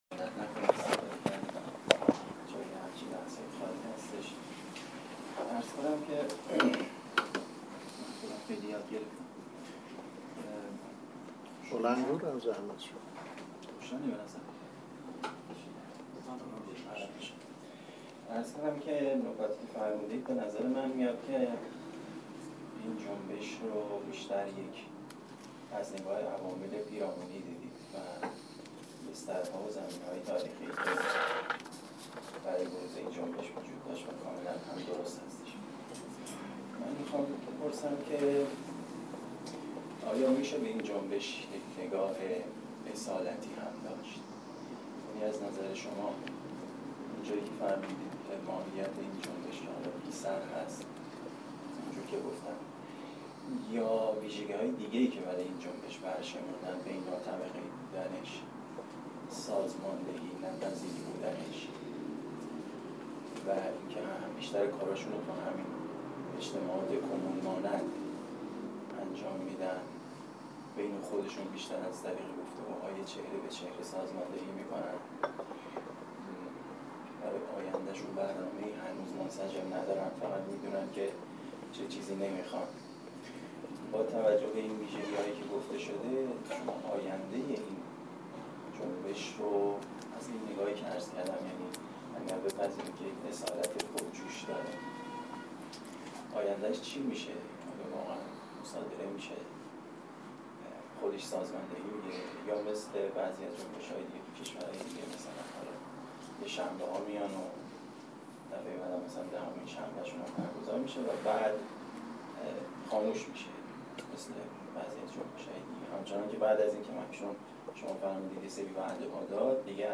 جنبش جلیقه زردها در فرانسه دلایل و ریشه ها سخنران:
سالن اندیشه